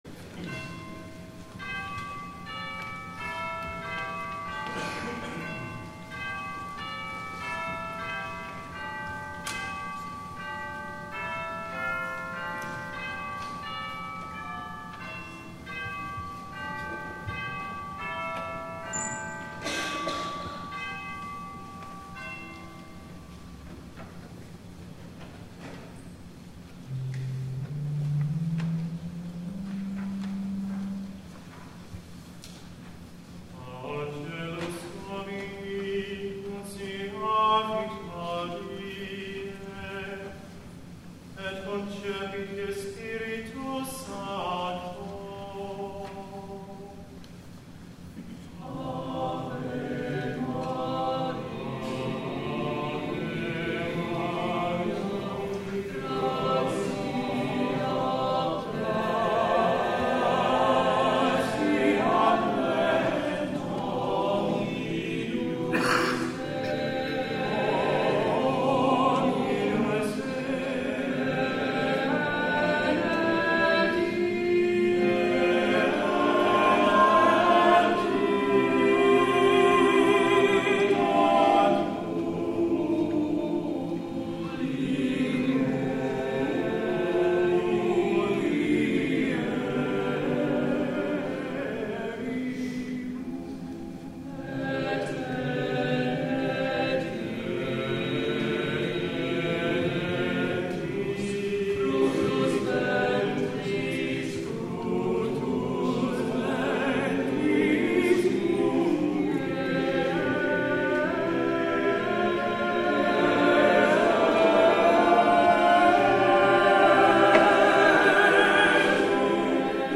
8 P.M. WORSHIP
THE INTROIT